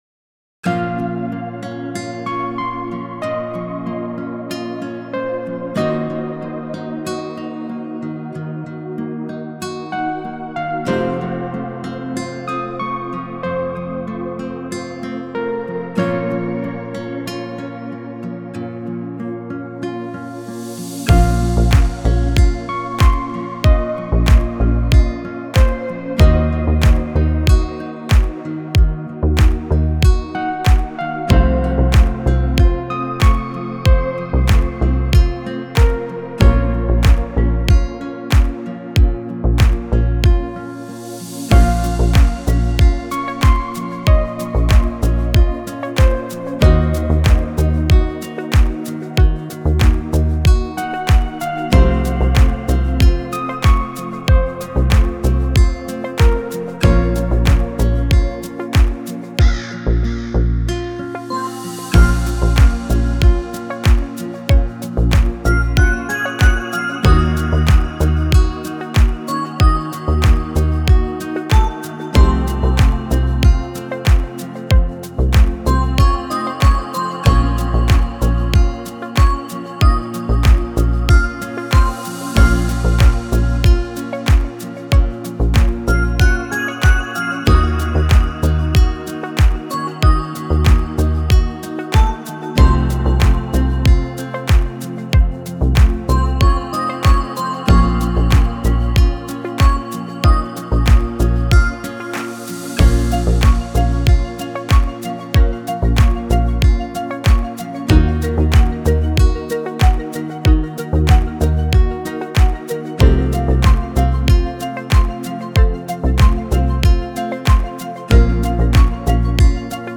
دیپ هاوس
ریتمیک آرام